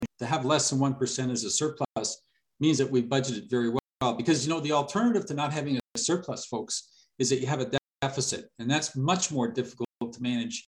Hastings County Community and Human Service meeting, March 9, 2022 (Screengrab)
Quinte West Councillor Terry Cassidy stated, “‘It is a responsible budget.”